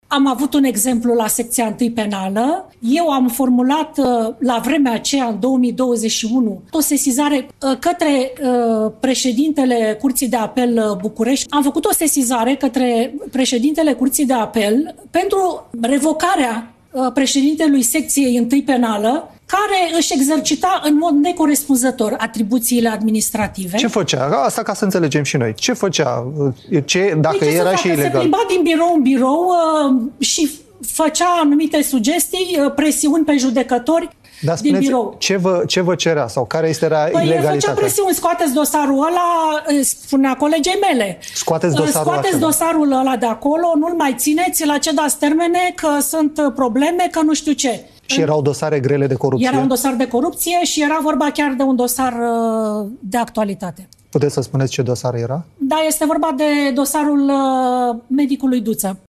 Într-un interviu acordat aseară postului Euronews România, fosta judecătoare Daniela Panioglu, cea care a judecat dosarul fostului președinte al CNAS Lucia Duță, a vorbit despre presiunile la care sunt supuși judecătorii.
11dec-09-Daniela-Panioglu-la-Euronews-despre-dosarul-Duta.mp3